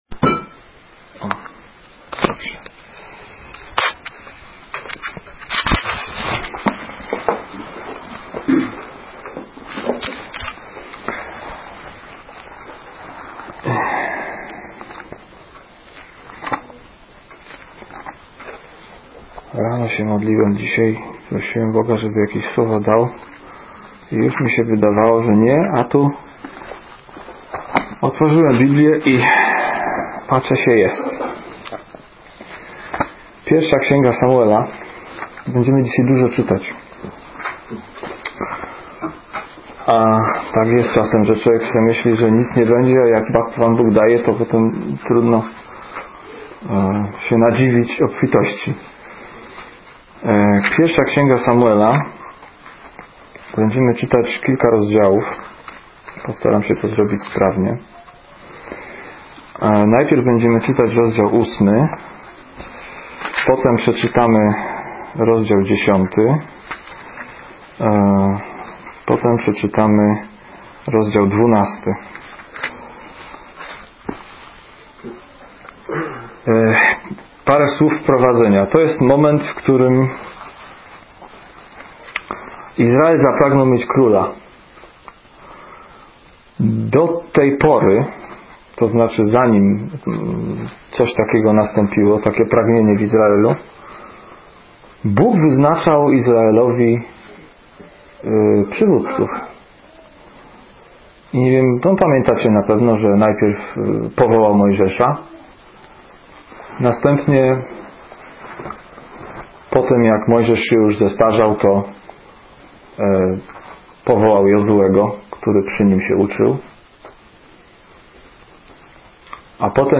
Ulica Prosta - Kazania z 2007